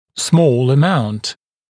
[smɔːl ə’maunt][смо:л э’маунт]небольшое количество